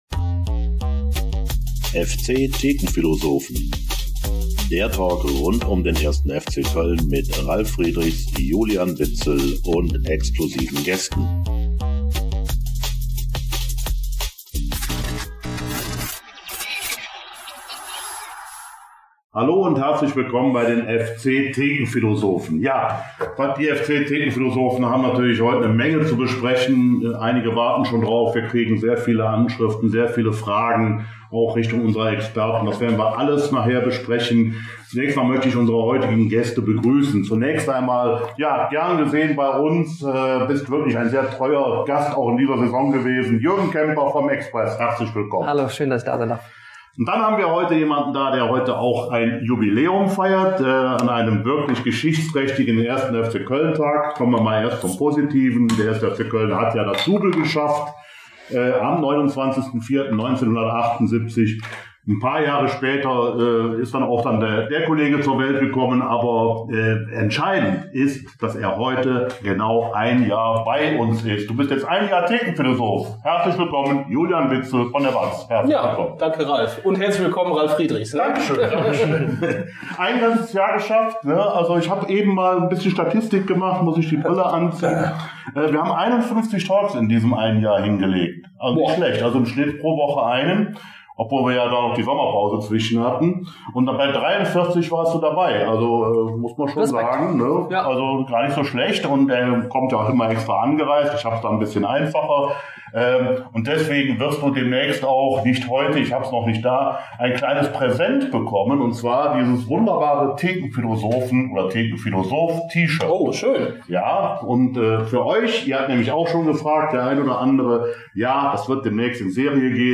Nach dem Katastrophen-Kick in Hannover: Was ist nur mit dem 1. FC Köln los? - Folge 93 ~ FC-Thekenphilosophen - Der Talk Podcast
Bei den "FC-Thekenphilosophen" wurde engagiert, konstruktiv und kritisch über die aktuelle Lage des 1. FC Köln diskutiert – zahlreiche Zuschauerfragen wurden aufgegriffen und intensiv besprochen.